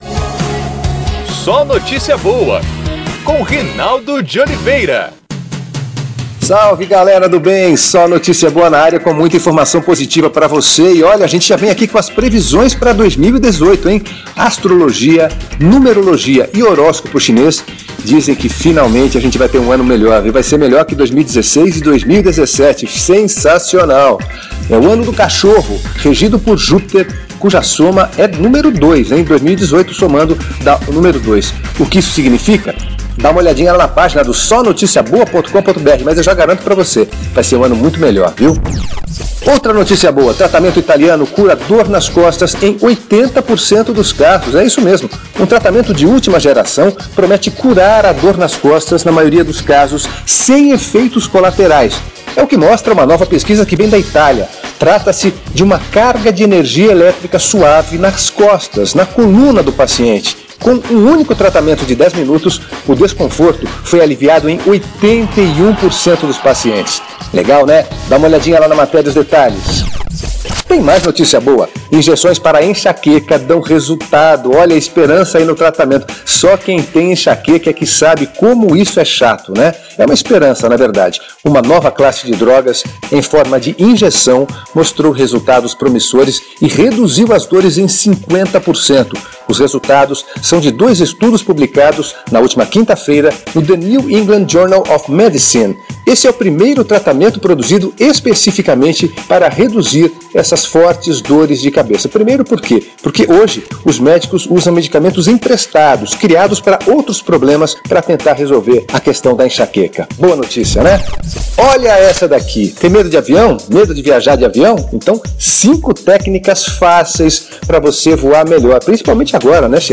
É o PodCast SóNotíciaBoa, exibido diariamente em pílulas na Rádio Federal, de Brasília.